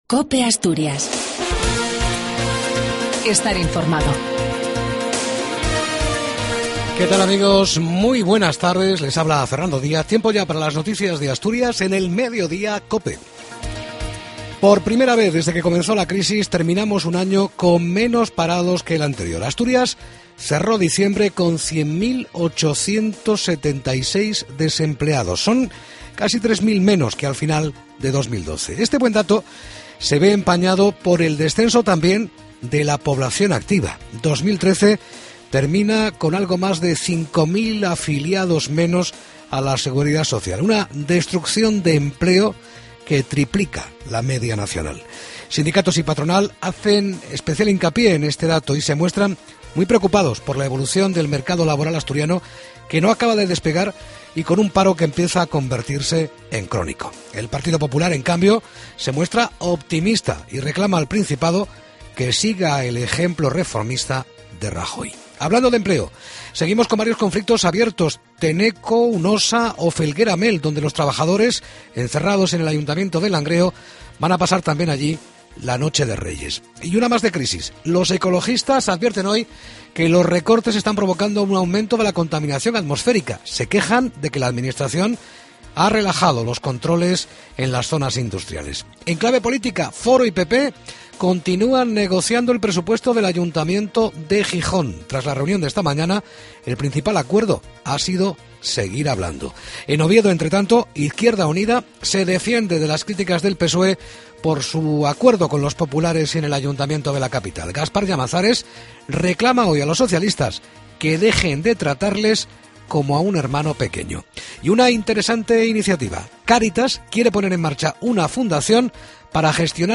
AUDIO: LAS NOTCIAS DE ASTURIAS Y OVIEDO AL MEDIODIA.